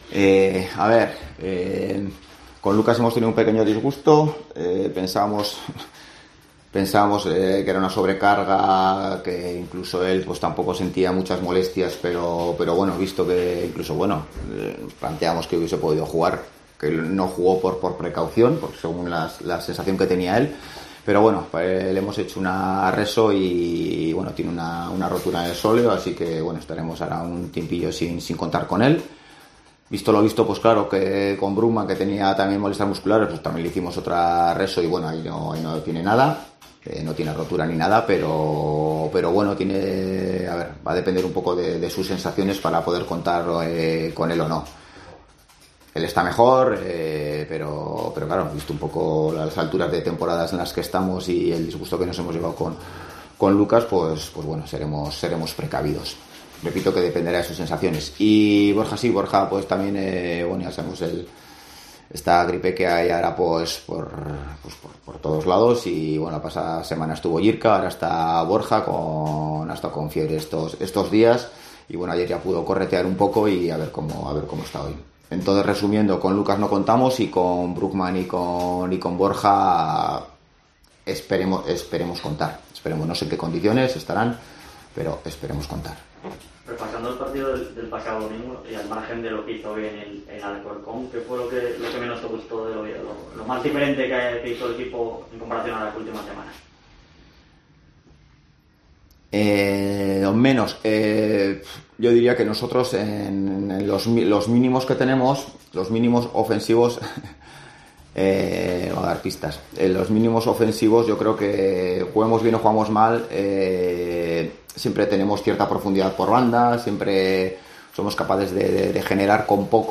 Rueda de prensa Ziganda (previa Mirandés)